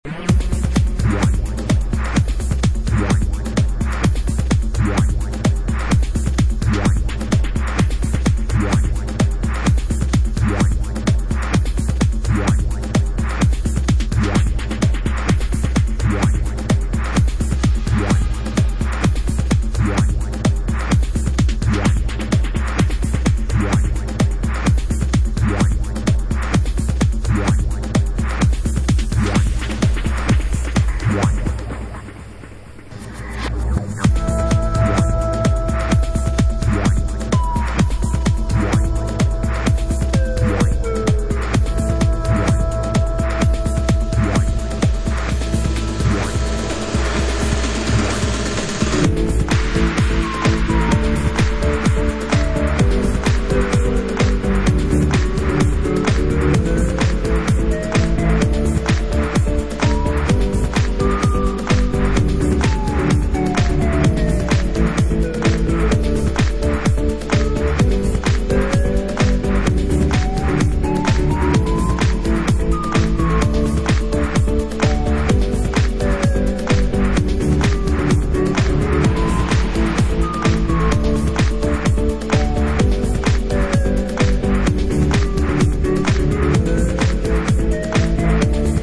Updated remix